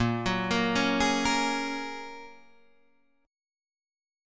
Bbm7#5 chord